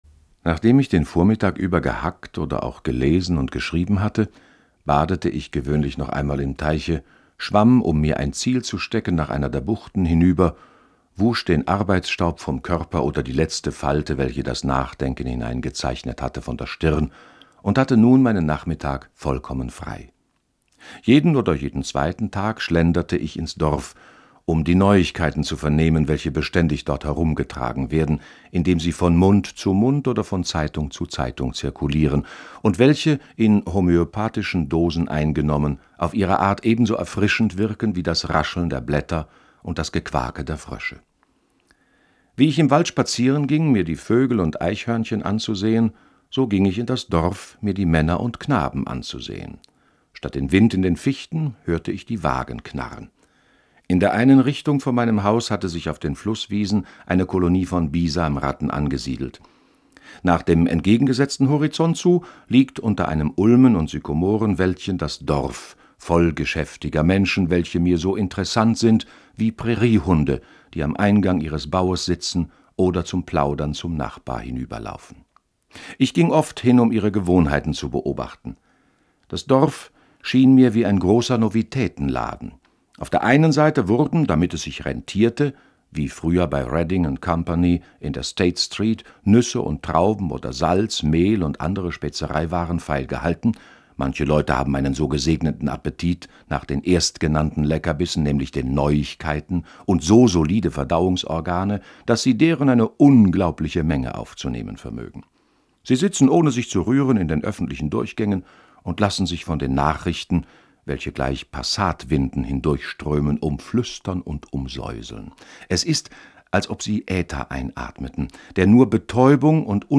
In unserer heutigen Lesung begleiten wir Thoreau bei einem seiner seltenen Ausflüge ins nächstgelegene Dorf, und wir fahren mit ihm hinaus zum Fischfang auf den Waldensee.